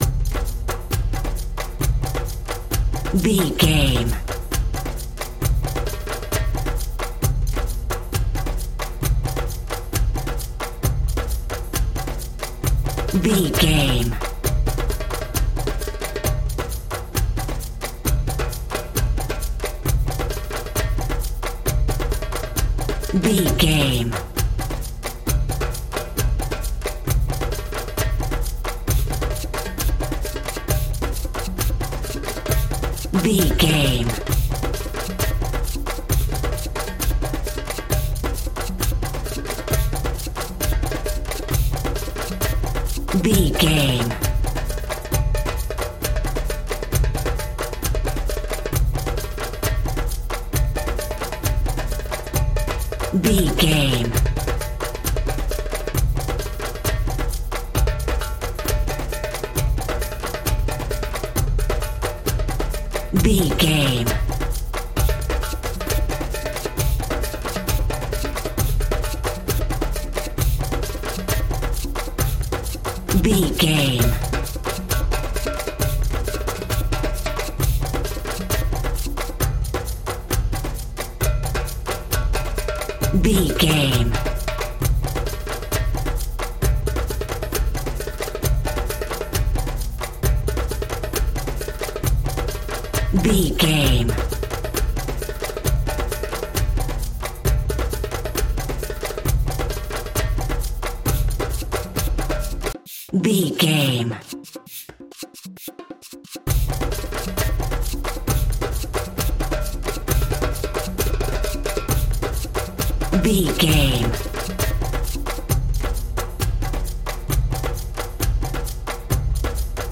Fast paced
Atonal
Fast
world beat
ethnic percussion